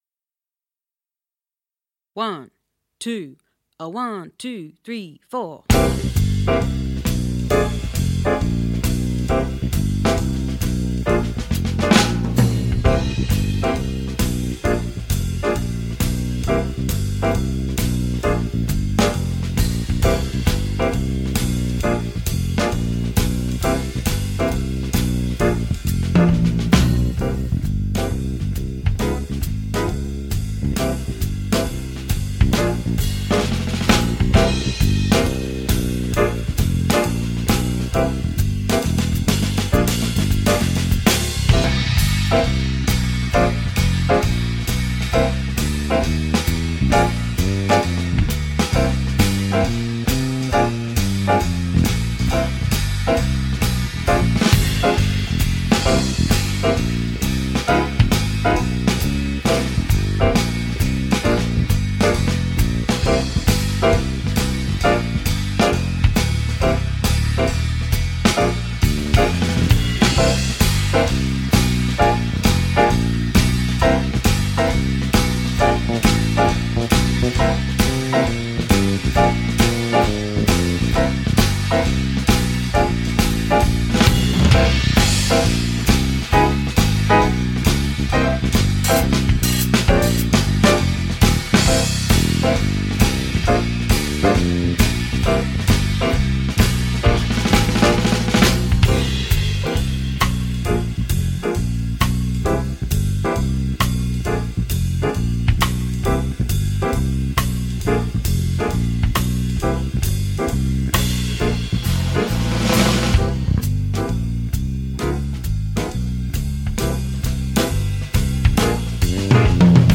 Modal Behaviour Bb Backing Cut